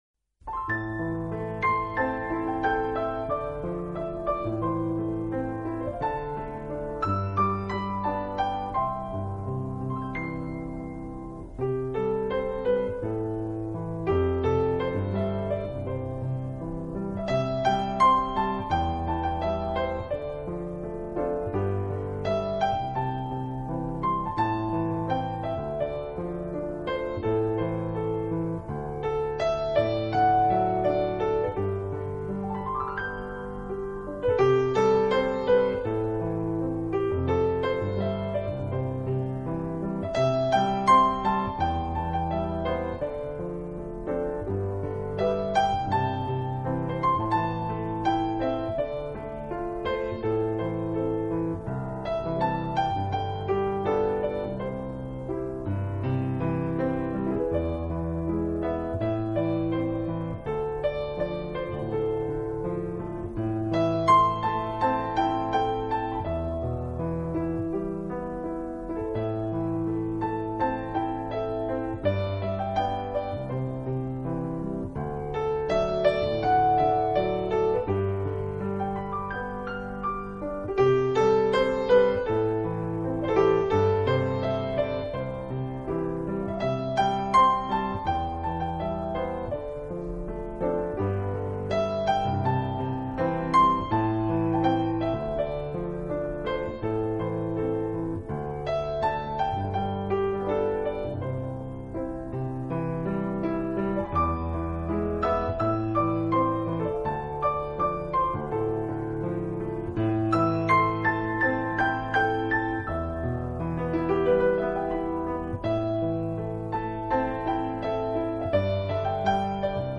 这是一套非常经典的老曲目经过改编用钢琴重新演绎的系列专辑。
而缠绵悱恻。
本套CD全部钢琴演奏，